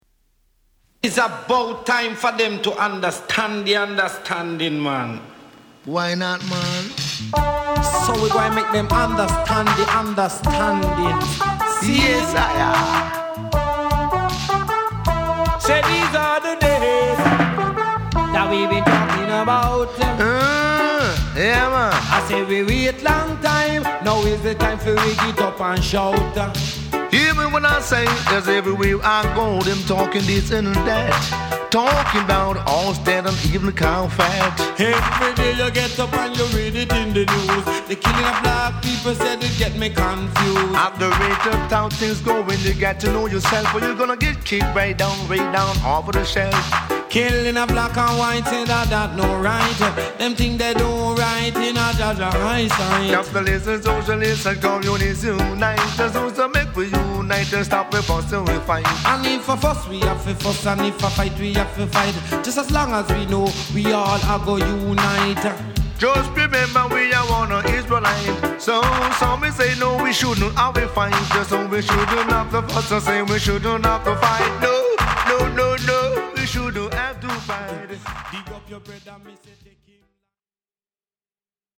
タイトなリズムと穏やかなキーボードの上音、軽快に絡む2人のヴォーカルも抜群の